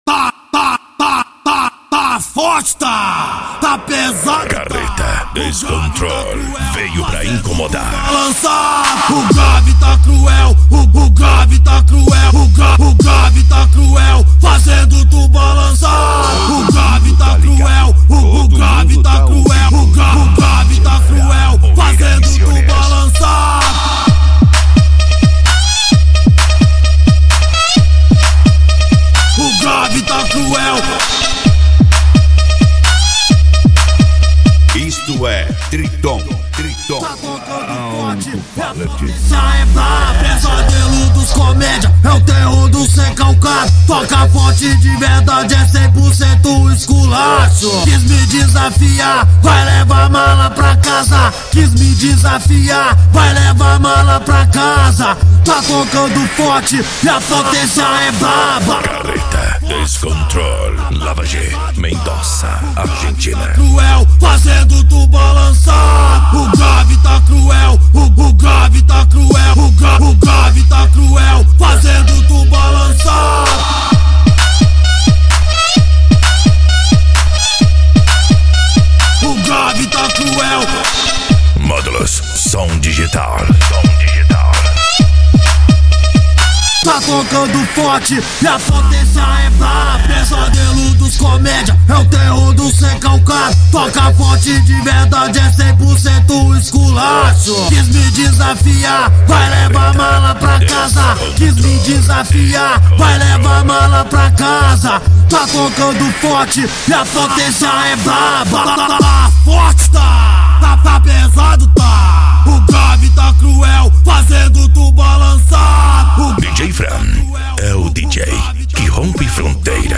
Bass
PANCADÃO
Remix